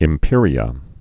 (ĭm-pîrē-ə)